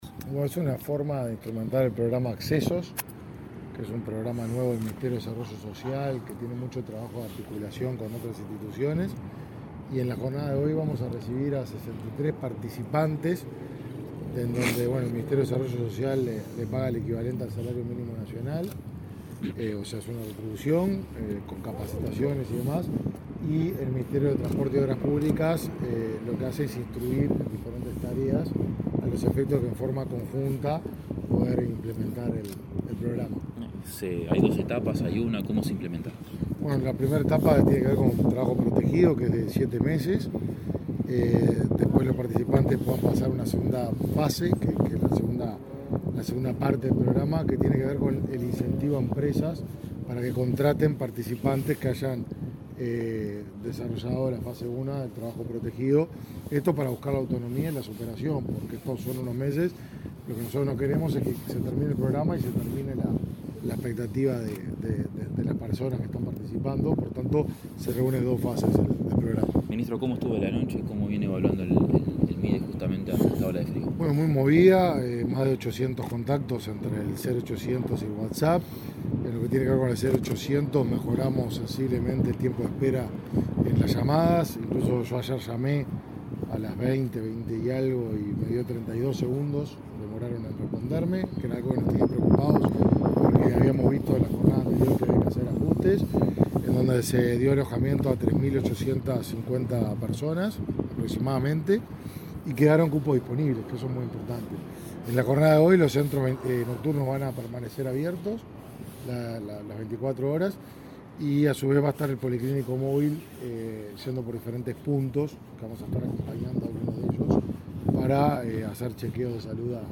Declaraciones a la prensa del ministro de Desarrollo Social, Martín Lema
El titular del Ministerio de Desarrollo Social (Mides), Martín Lema, y el subsecretario de Transporte, Juan José Olaizola, recibieron este martes 31 a 60 participantes del programa Accesos, del Mides, que serán capacitados en los Talleres Cerrito, de la segunda cartera citada, en el marco de un programa socioeducativo y laboral. Antes del acto, Lema dialogó con la prensa.